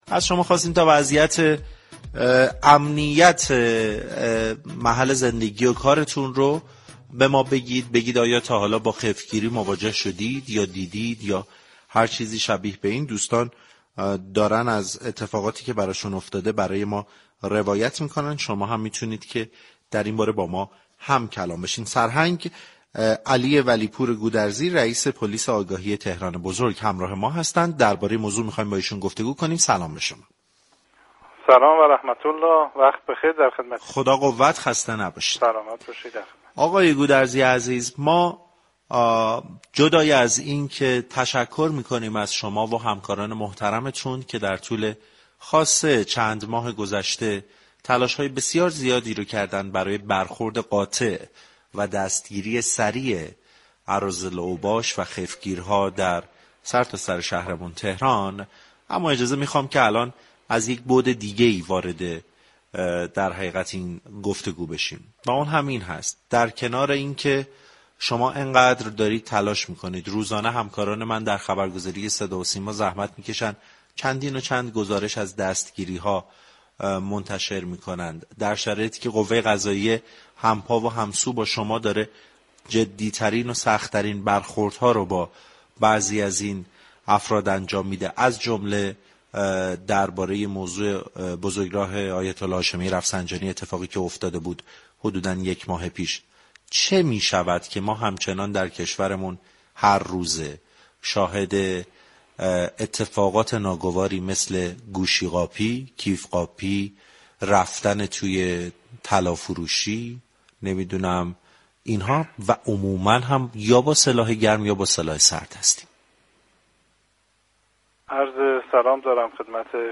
وی در پایان این گفت و گوی رادیویی تاكید كرد: خط قرمر ما آسایش مردم است .